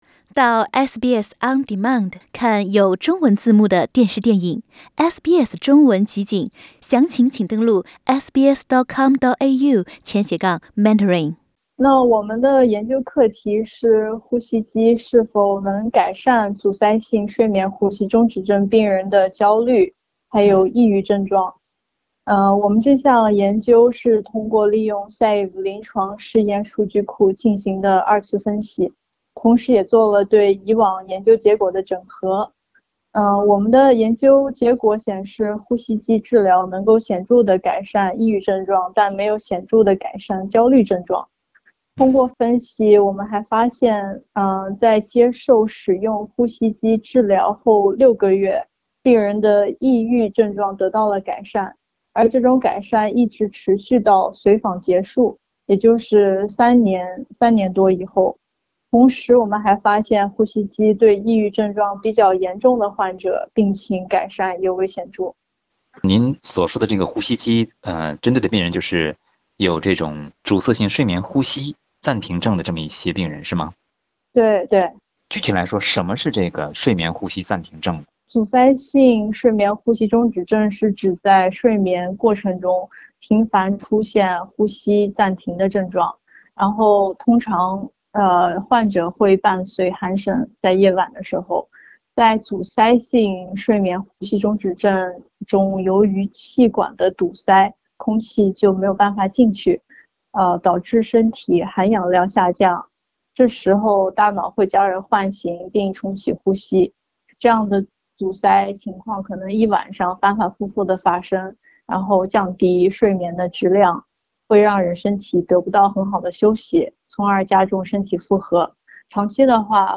请您点击收听详细的采访内容